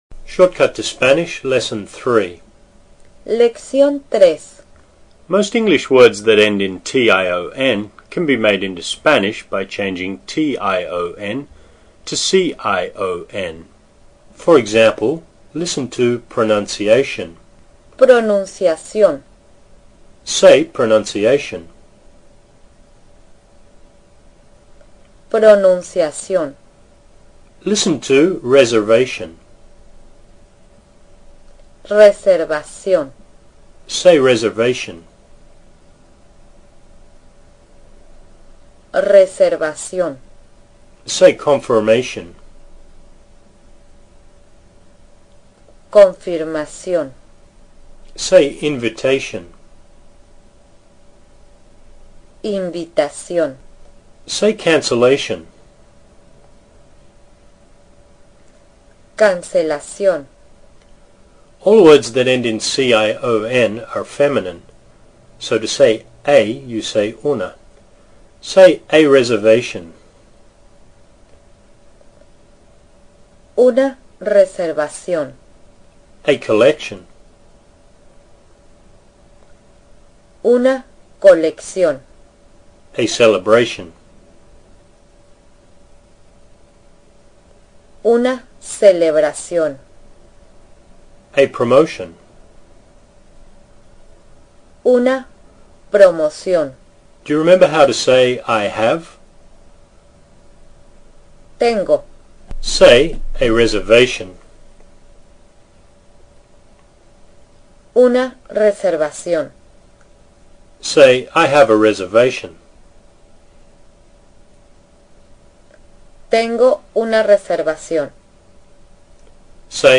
Here are 4 MP3 Spanish lessons to get you started.